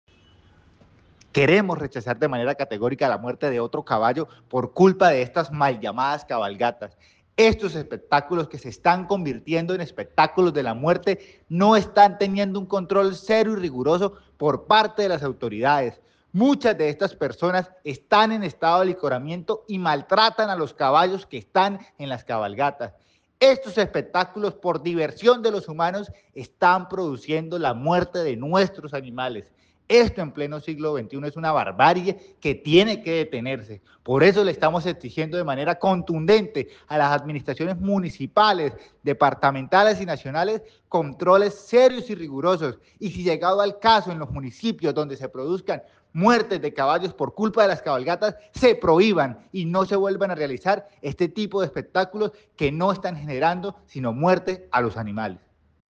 Danovis Lozano, diputado de Santander